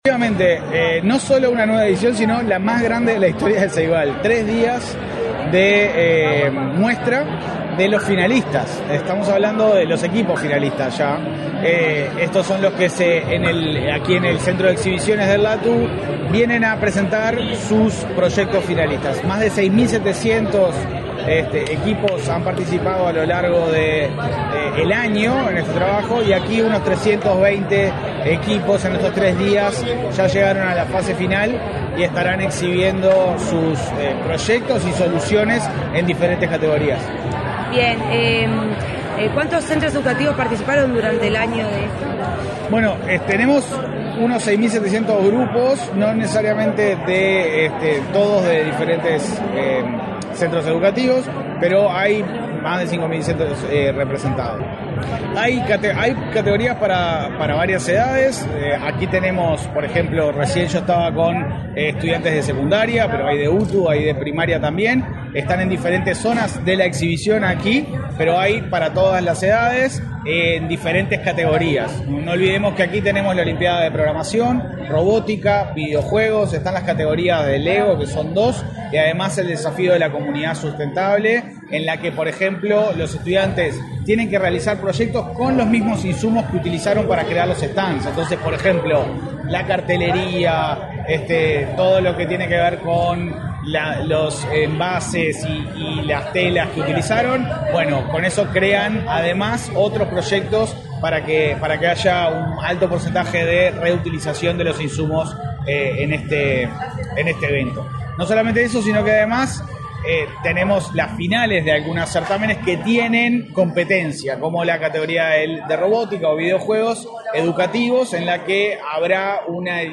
Entrevista al titular de Ceibal, Leandro Folgar
Entrevista al titular de Ceibal, Leandro Folgar 04/11/2024 Compartir Facebook X Copiar enlace WhatsApp LinkedIn El presidente de Ceibal, Leandro Folgar, dialogó con Comunicación Presidencial, durante la Olimpíada de Robótica, Programación y Videojuegos que ese organismo realizó este lunes 4 en el Laboratorio Tecnológico del Uruguay.